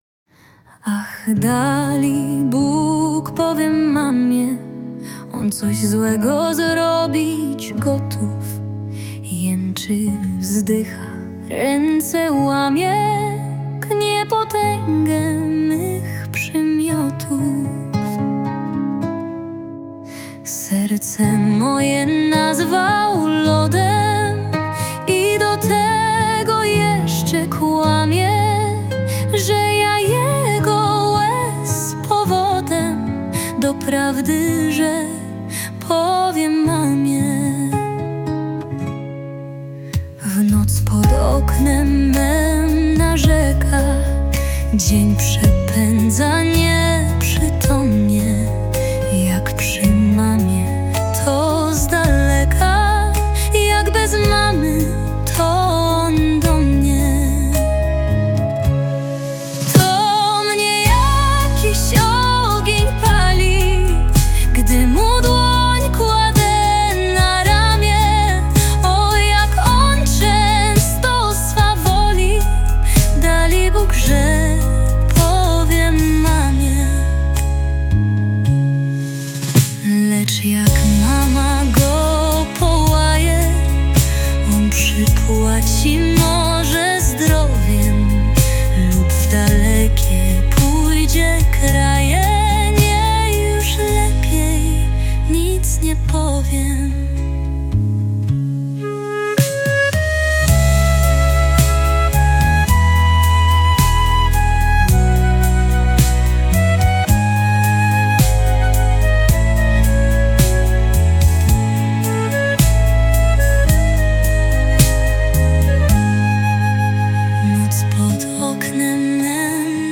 No to na ludowo! Utwór nieznanego autorstwa pt. 'Ach dalibóg powiem mamie'.
No to zrobiłem cover acapella.